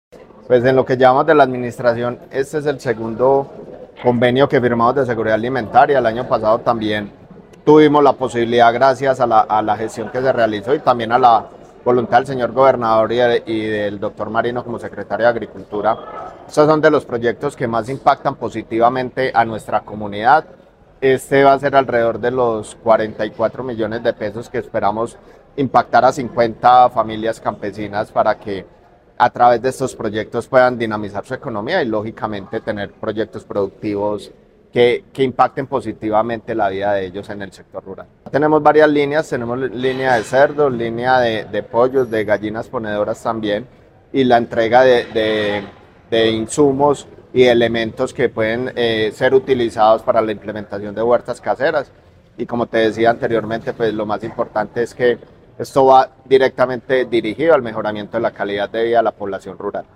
Néstor Javier Ospina Grajales, alcalde de Viterbo.